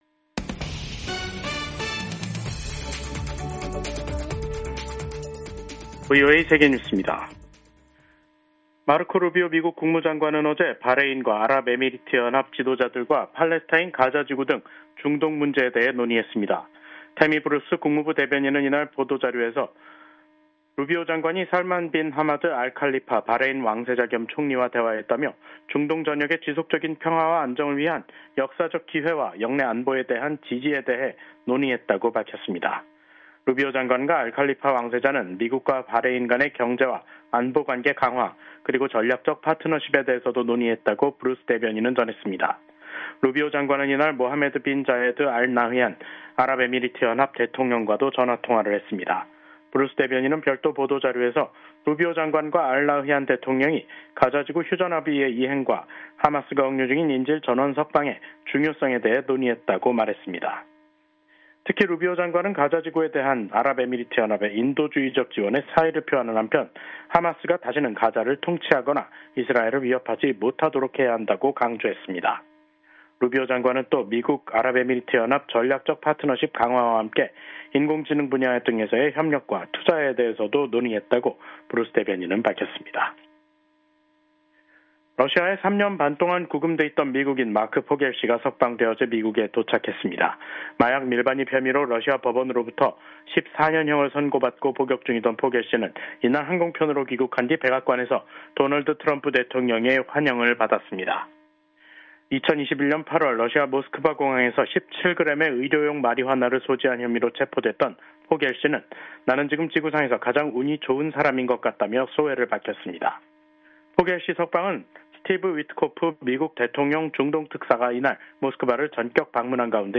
세계 뉴스와 함께 미국의 모든 것을 소개하는 '생방송 여기는 워싱턴입니다', 2025년 2월 12일 저녁 방송입니다. 도널드 트럼프 미국 대통령이 11일 백악관을 방문한 압둘라 2세 요르단 국왕에게 가자지구 인수 계획을 재차 강조했습니다. 도널드 트럼프 행정부 출범 후 첫 뮌헨안보회의가 14일부터 독일에서 시작됩니다. J.D. 밴스 미국 부통령이 프랑스 파리에서 열린 인공지능(AI) 행동 정상회의에 참석해 유럽의 AI 규제 정책을 비판했습니다.